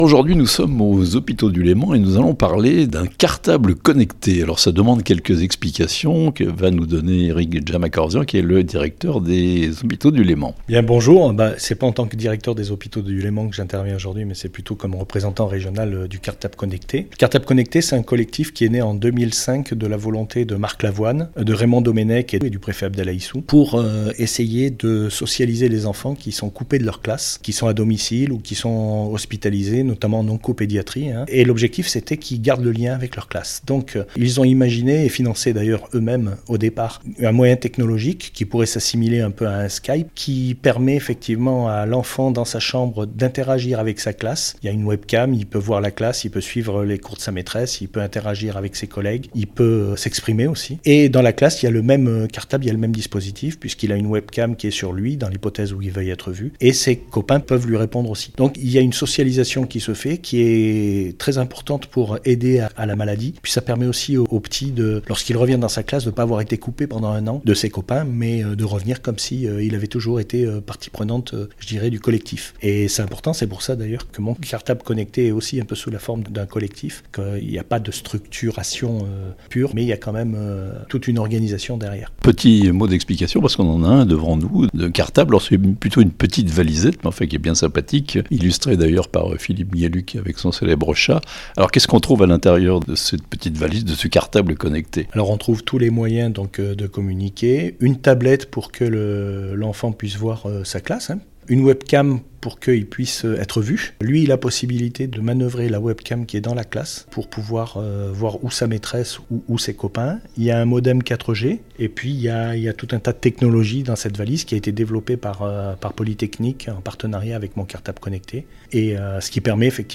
Un cartable connecté pour les enfants hospitalisés (interview)